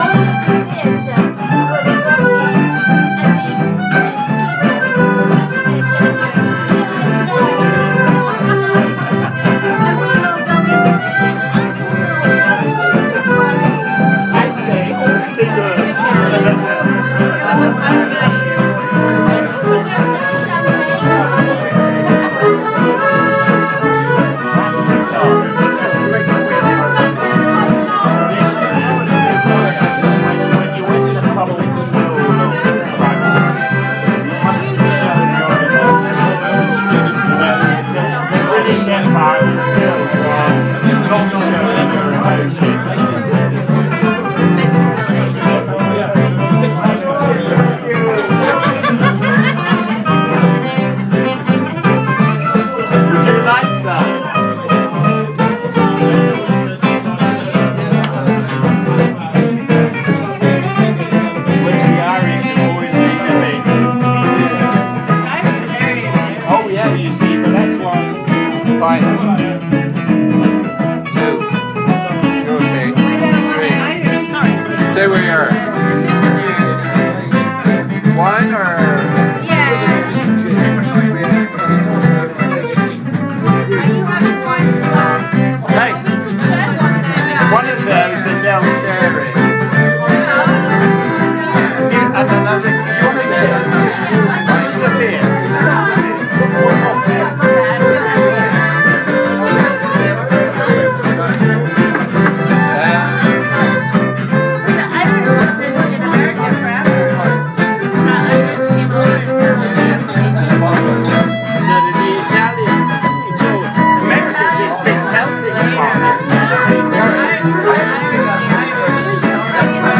Gypsy Jazz, bouncy